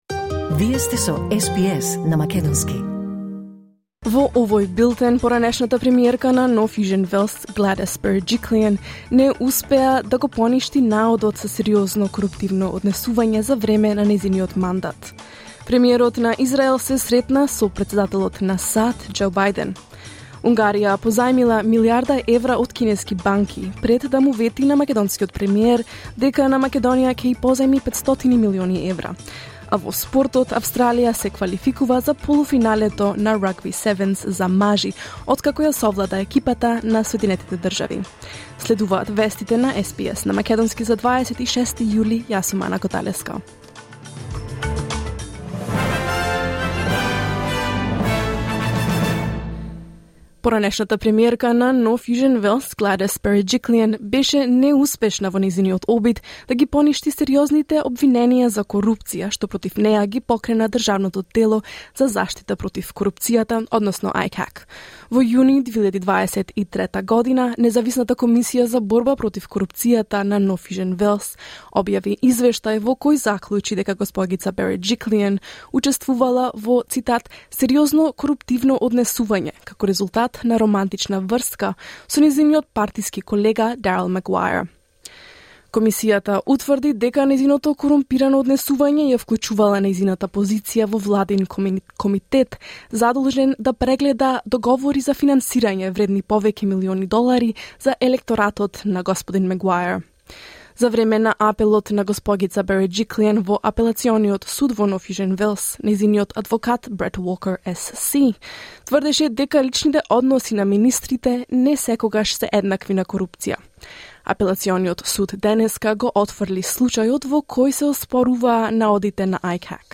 SBS News in Macedonian 26 July 2024